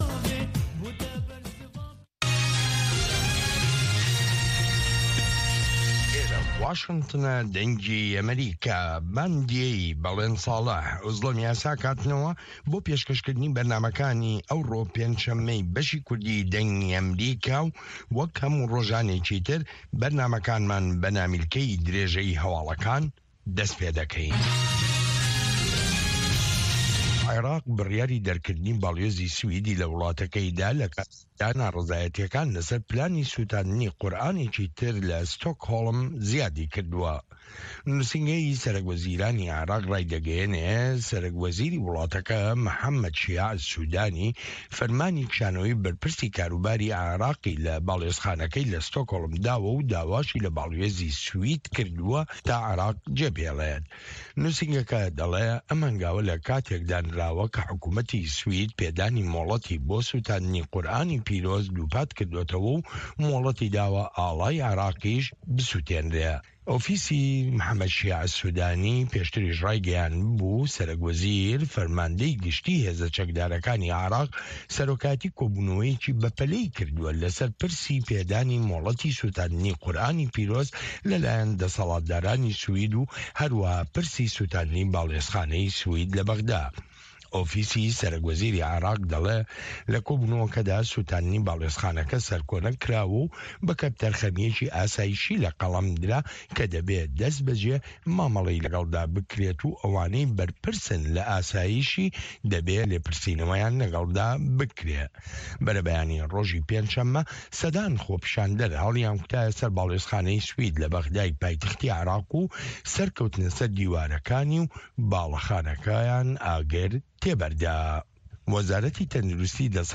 Nûçeyên 1’ê paşnîvro
Nûçeyên Cîhanê ji Dengê Amerîka